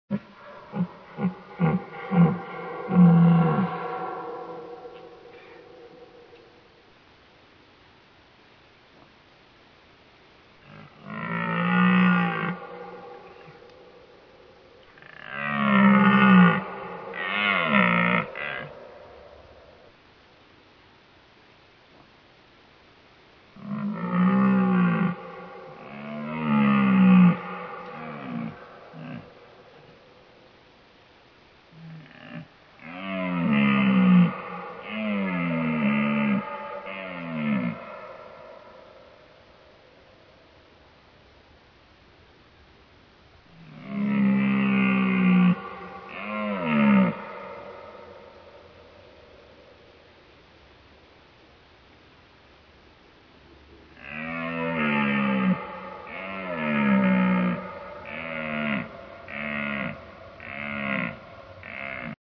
Звуки оленя
Олень – есть такой вариант